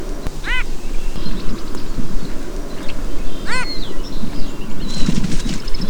Wilson's Phalarope
Falaropo de Wilson
Phalaropus tricolor